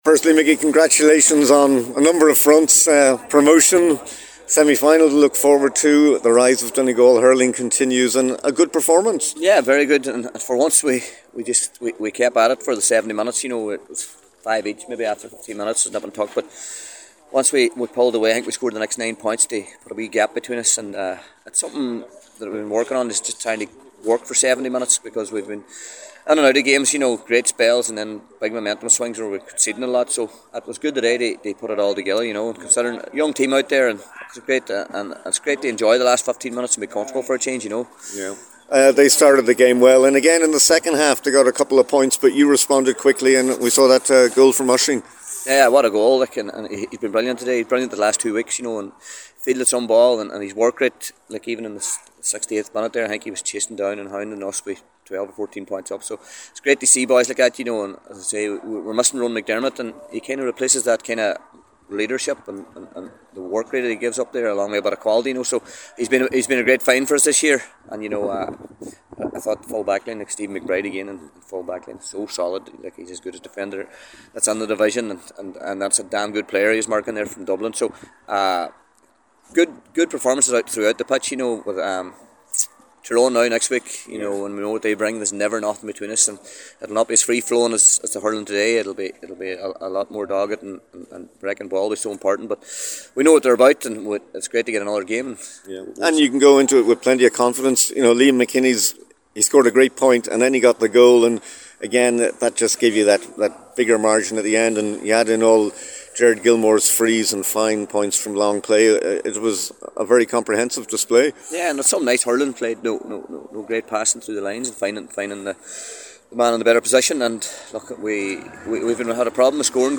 after the game…